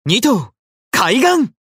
刀剑乱舞_Jizou-doubleattackcallout.mp3